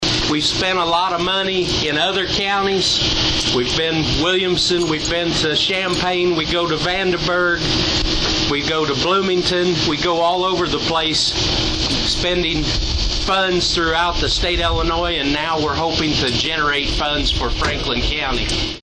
BENTON – State and county leaders, first responders, and community members showed up for Wednesday’s ribbon cutting ceremony and open house at the new Franklin County Coroner’s Office and Morgue in Benton.